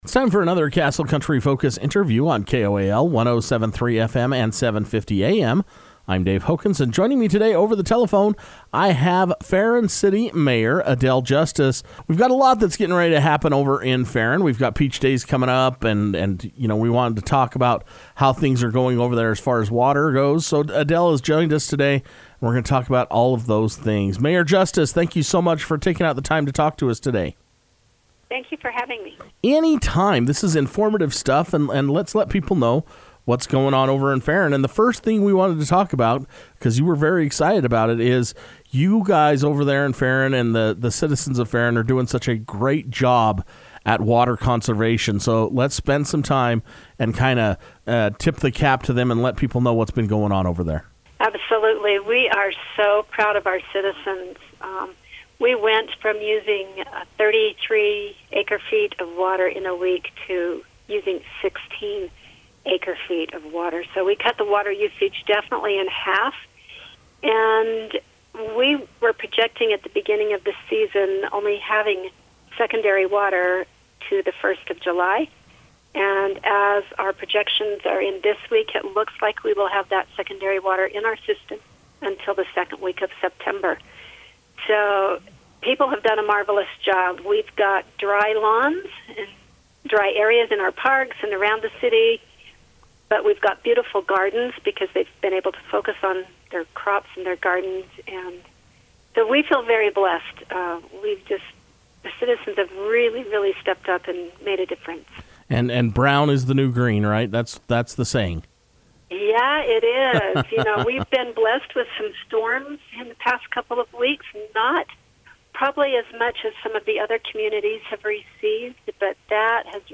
It’s time for another Ferron City update with Mayor Adele Justice to talk about water conservation, trails, tourism and Peach Days.